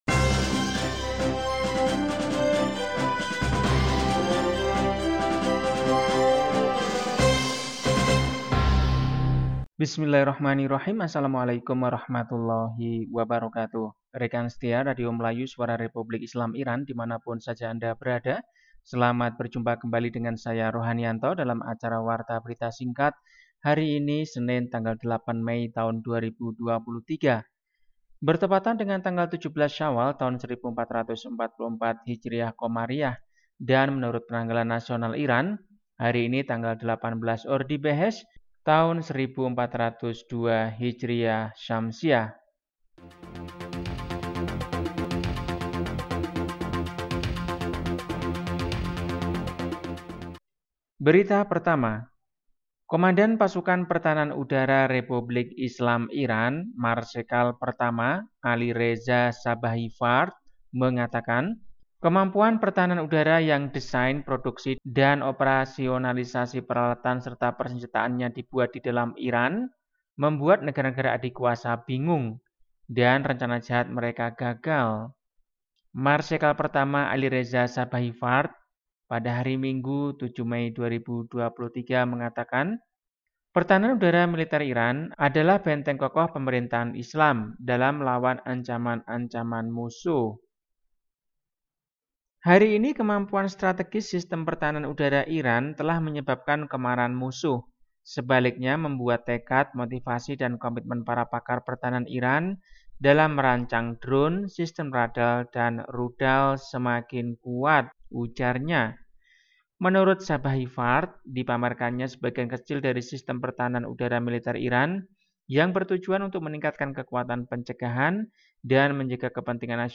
Warta Berita 8 Mei 2023